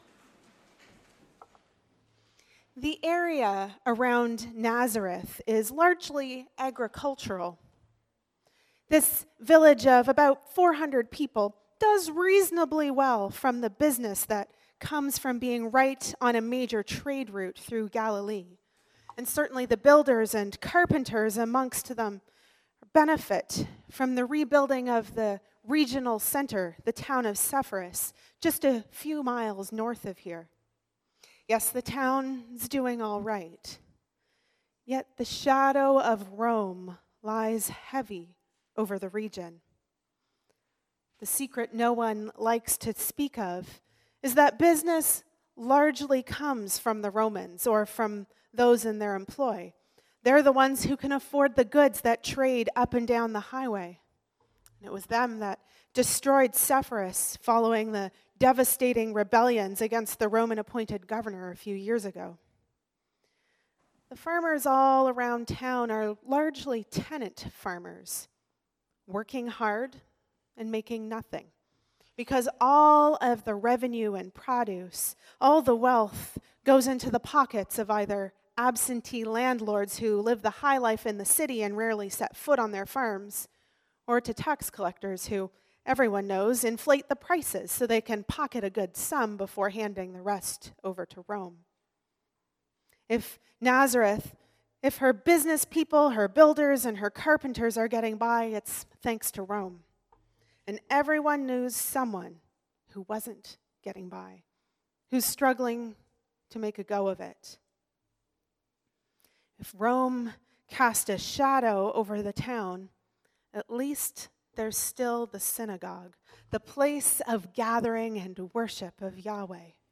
Sermons | Parish of the Valley